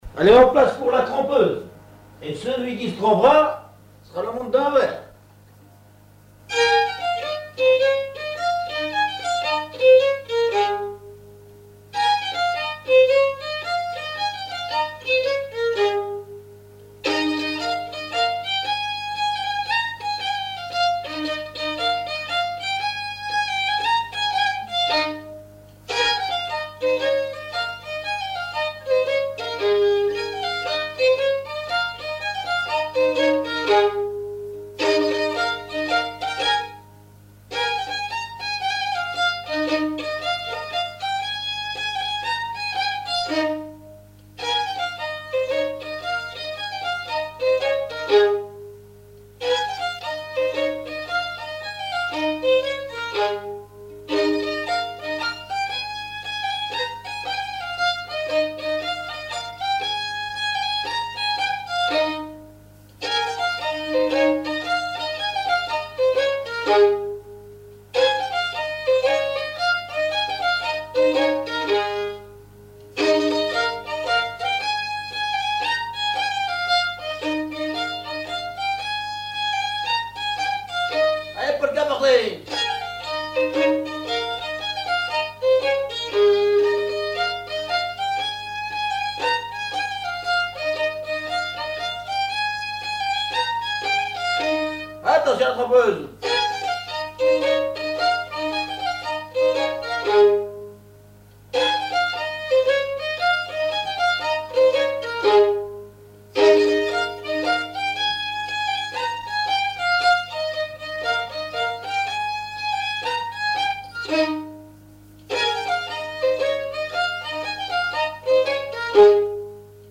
Laforte : non-référencée - V, F-** Coirault : non-référencée - 0074** Thème : 0074 - Divertissements d'adultes - Couplets à danser Résumé : T'en iras-tu dans l'autre monde, sans faire marcher ton chalumia.
Pièce musicale inédite